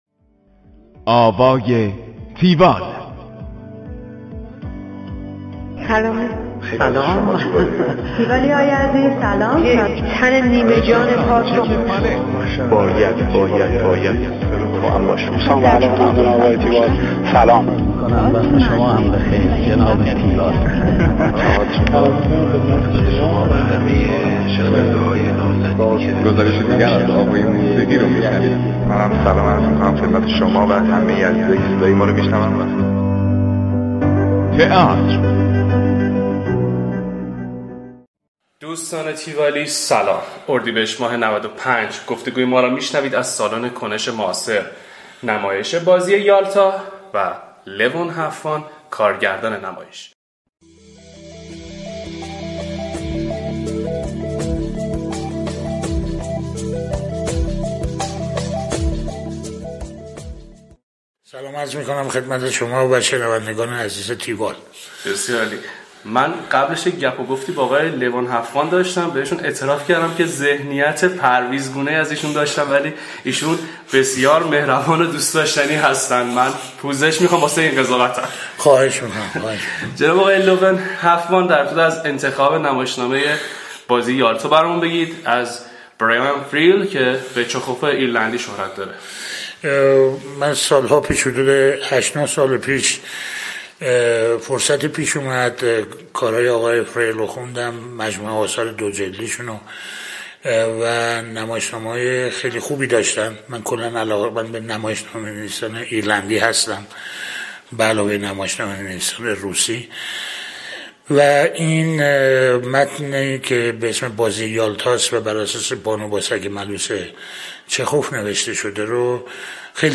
tiwall-interview-levonhaftvan.mp3